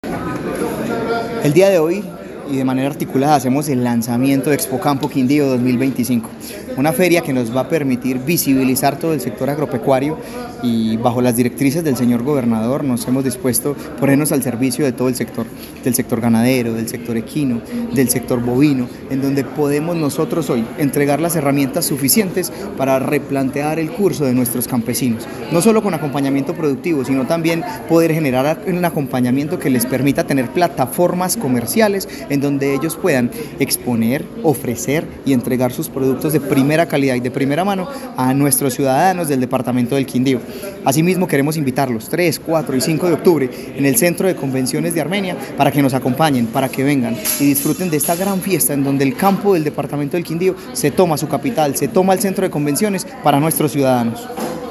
Audio del secretario de Agricultura, Bryant Naranjo Raigoza: